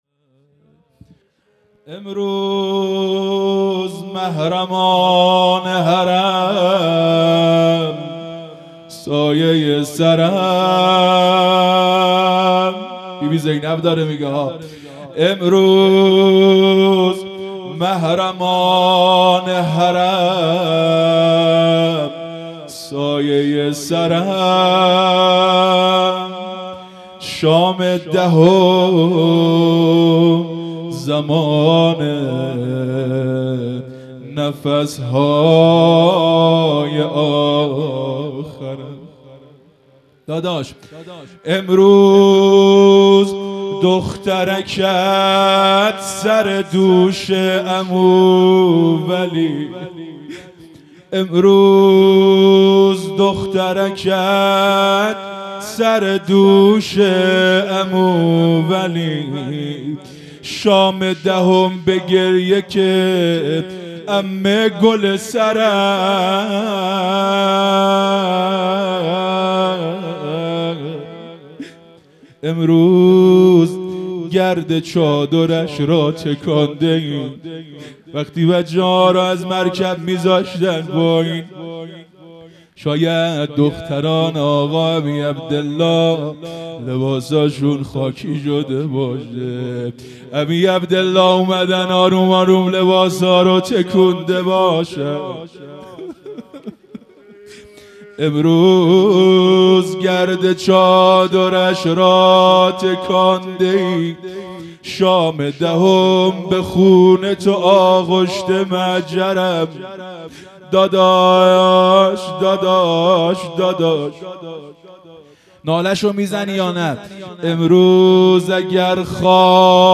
مراسم عزاداری محرم الحرام ۱۴۴۳_شب دوم